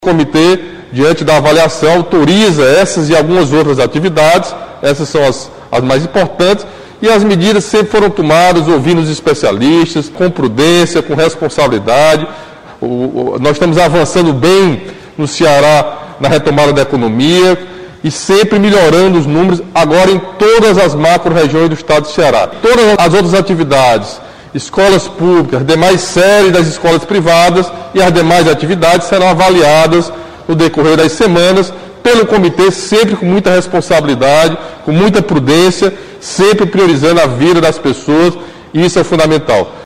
O governador Camilo Santana reforçou que as decisões seguiram as decisões e protocolos definidos pelo Comitê Científico.